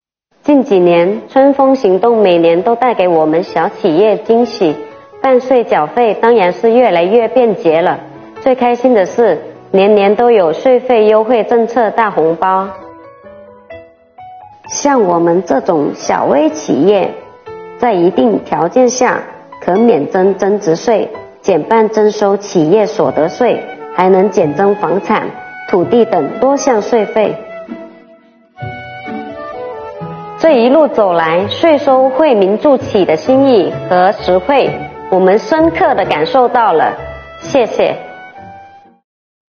从2月起，本公众号将陆续播出“便民办税春风行动”十周年系列访谈。